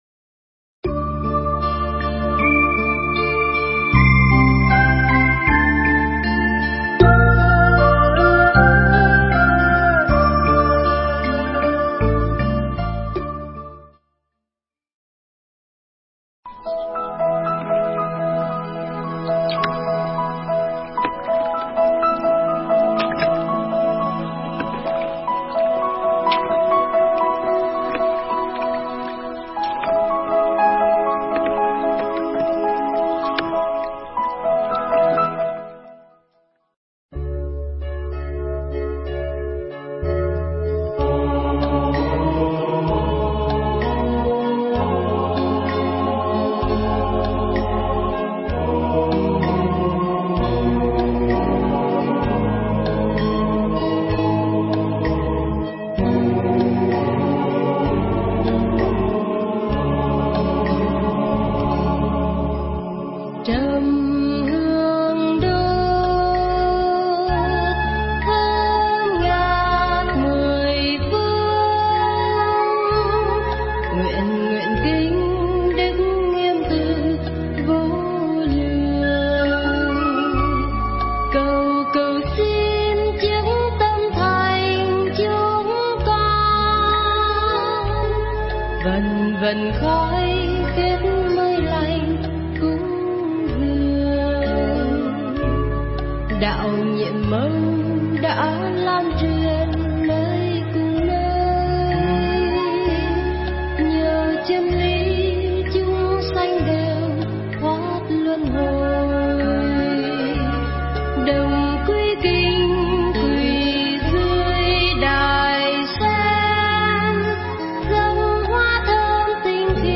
Nghe Mp3 thuyết pháp Ước Mơ Bình Thường
Mp3 pháp thoại Ước Mơ Bình Thường